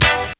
HORN HIT-R.wav